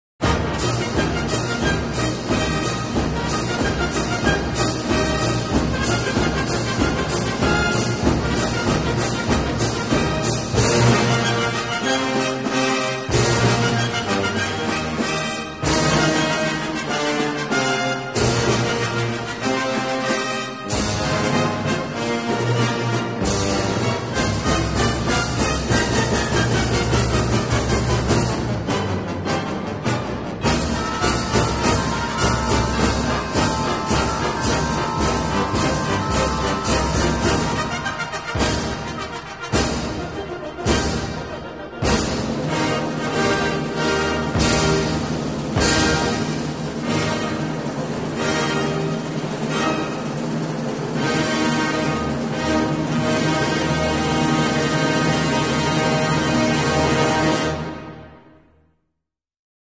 New American regiment burning sound#gutsandblackpowder sound effects free download